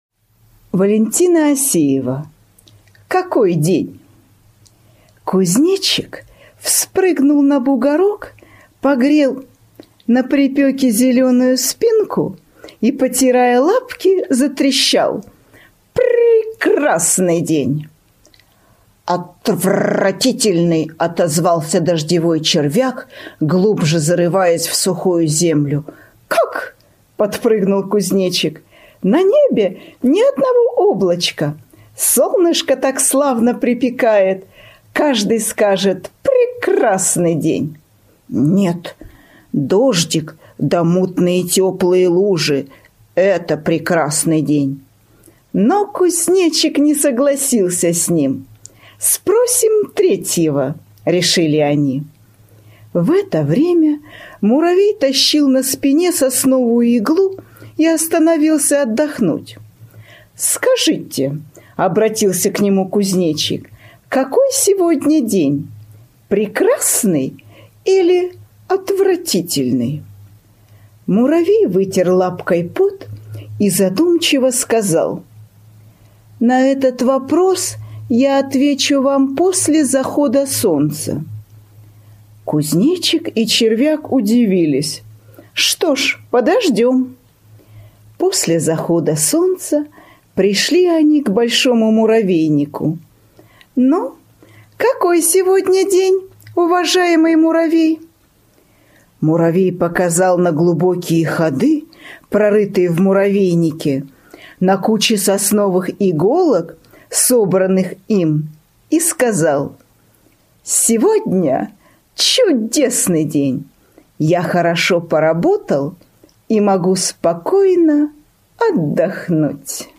Аудио сказка Валентины Осеевой "Какой день" представляет собой увлекательное и поучительное рассказ о том, как разные животные воспринимают один и тот же день по-разному. Она рассказывает об интересной беседе кузнечика, дождевого червяка и муравья о том, что делает день хорошим или плохим.